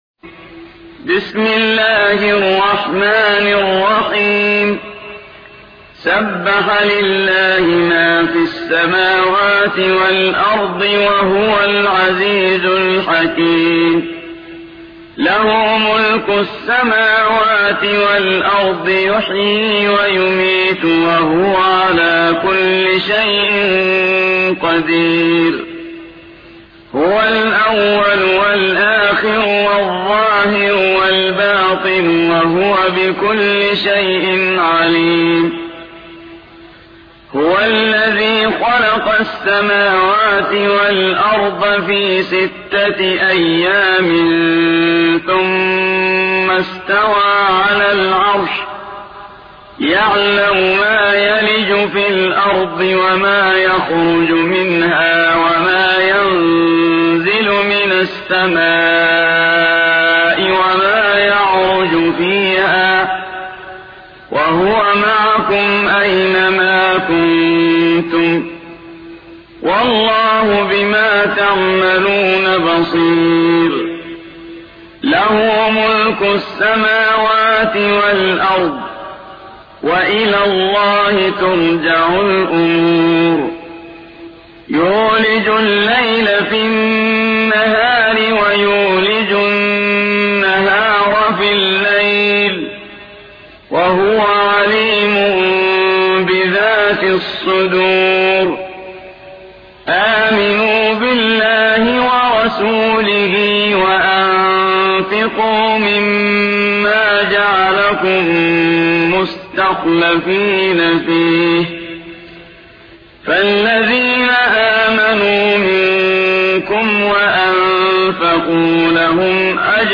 57. سورة الحديد / القارئ